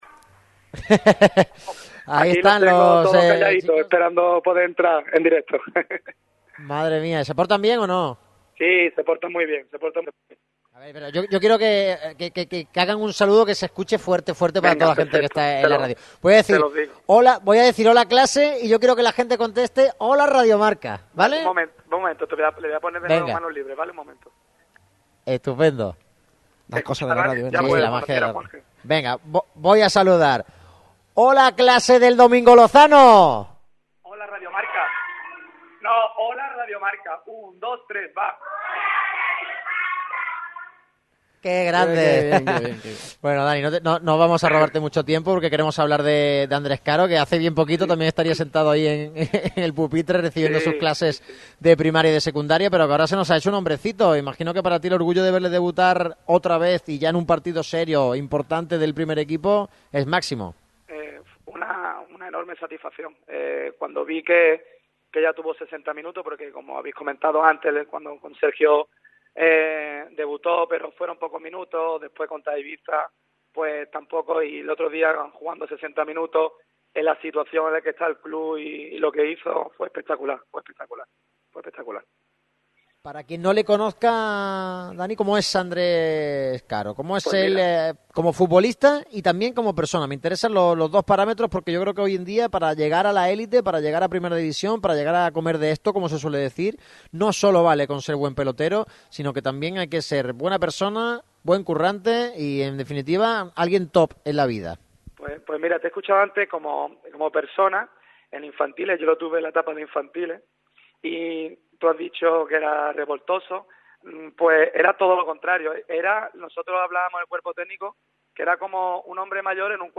ha pasado por el micrófono rojo de Radio MARCA Málaga